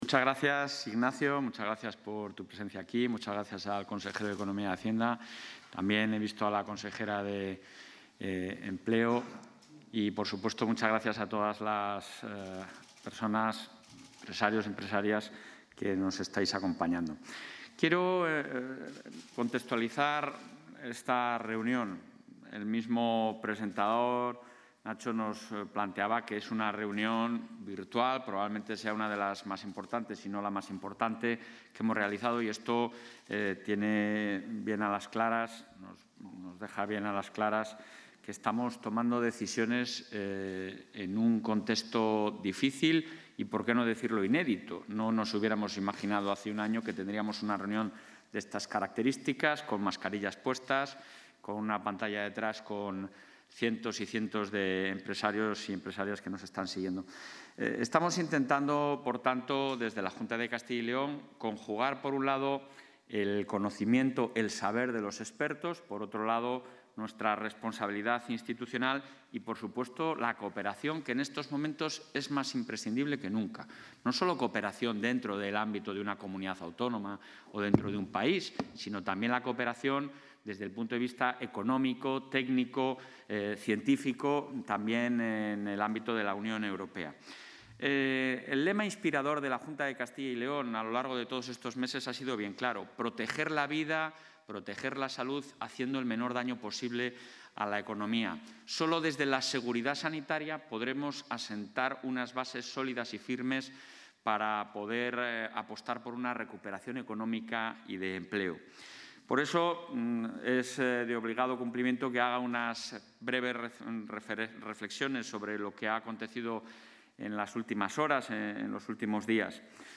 Intervención del presidente de la Junta.
El presidente del Ejecutivo Autonómico, Alfonso Fernández Mañueco, ha mantenido esta mañana un encuentro telemático con más de 500 empresarios de la Comunidad, en el que se ha explicado el trabajo que se está llevando a cabo desde la Junta de Castilla y León en torno a los fondos europeos y al resto de instrumentos financieros europeos de aplicación en Castilla León para hacer frente a los desafíos planteados por la pandemia COVID-19.